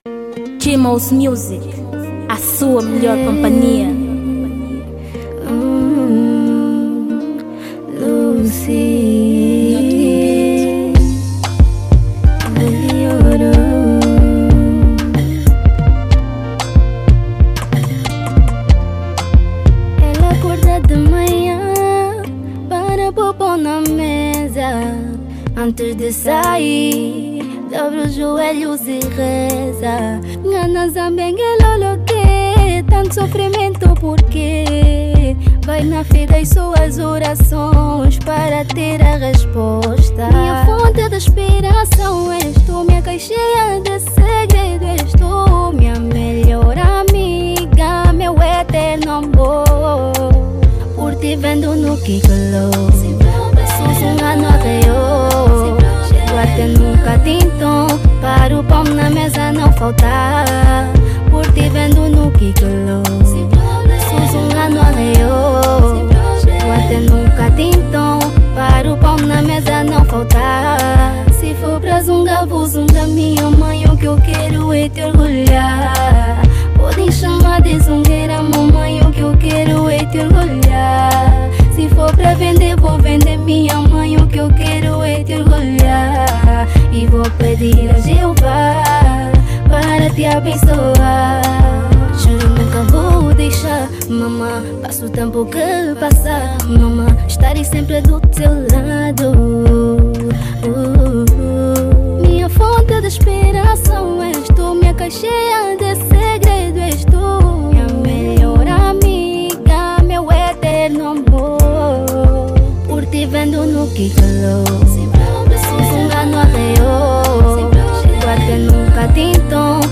Gênero: Zouk